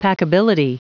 Prononciation du mot : packability